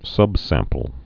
(sŭbsămpəl)